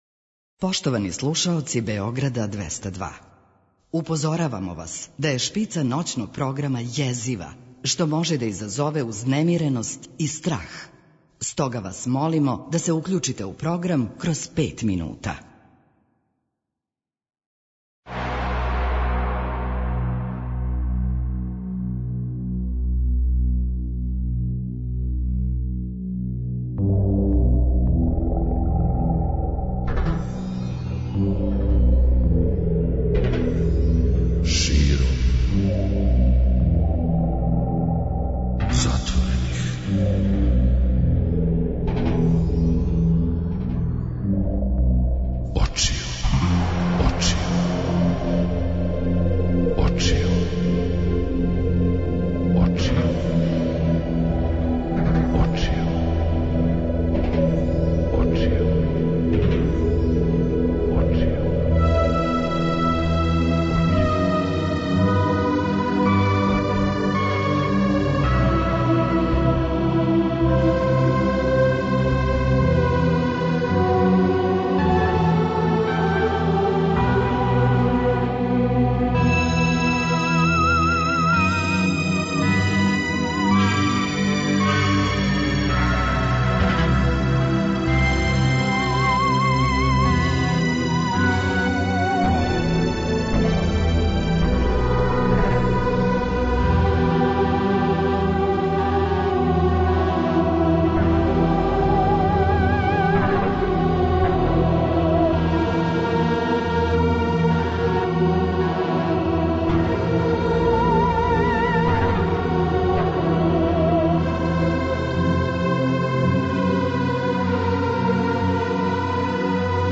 Слушаоци ће имати прилике и да чују неколико најтужнијих песама свих времена по анкетама које су спроведене широм света.